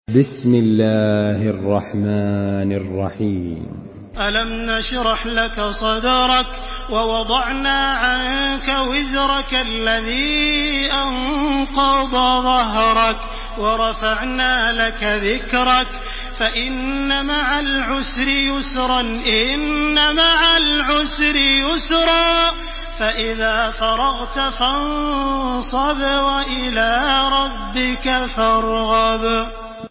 Surah Inşirah MP3 by Makkah Taraweeh 1429 in Hafs An Asim narration.
Murattal